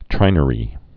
(trīnə-rē)